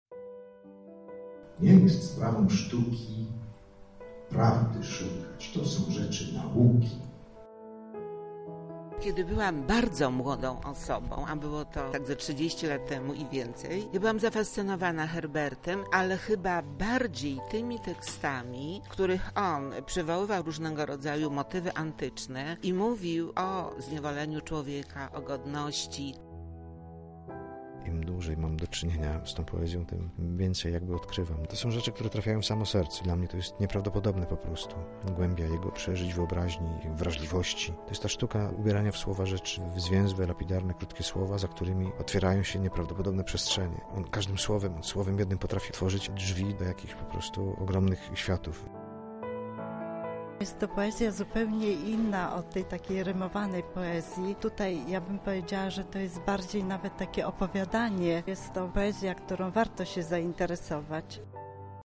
Jeden najważniejszych i najczęściej tłumaczonych poetów polskich został upamiętniony w Lublinie.
Widzowie mieli również okazję wysłuchać twórczości Zbigniewa Herberta w wykonaniu Dariusza Kowalskiego: